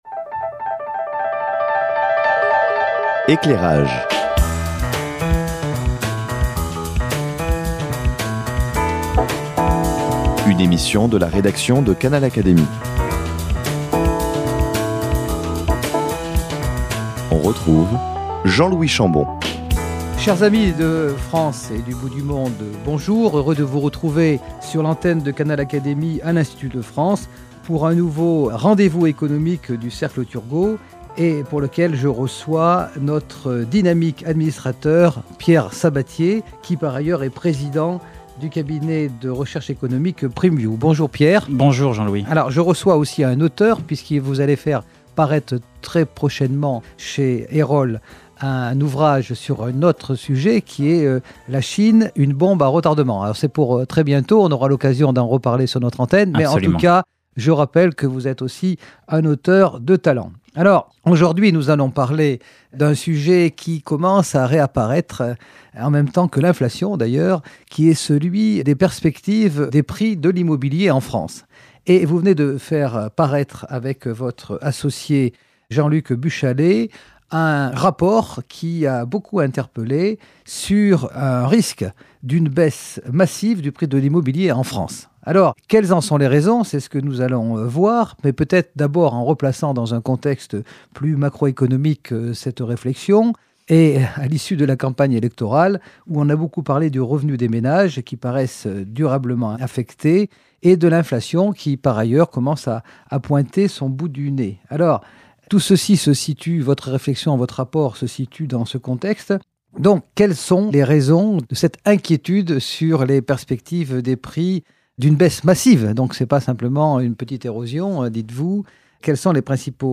Retrouvez d'autres émissions d'économie sur Canal Académie